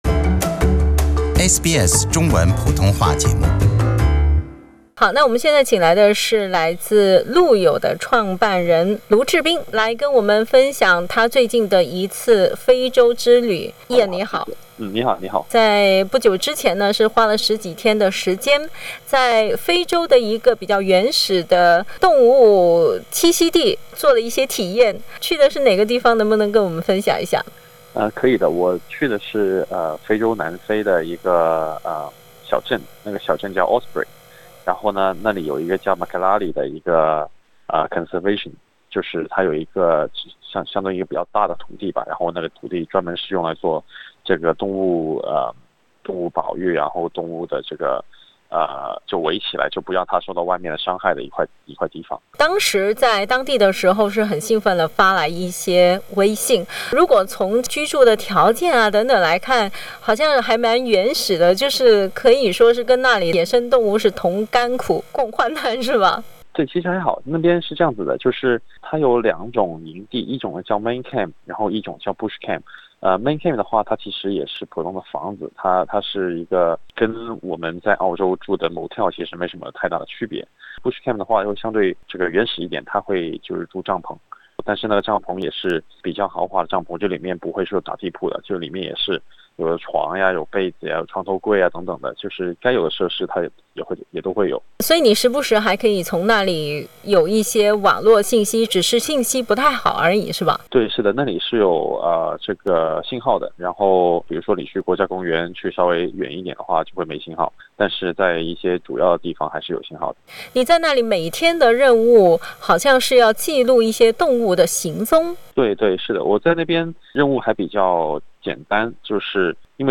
在和野生动物接触的过程中，更深刻地了解大自然和环保的议题。 他说，现在很多年轻的海外华人都很关注环保和野生动物的话题。 在采访中，他还提及了和一头调皮好奇的大象面对面的难忘场面。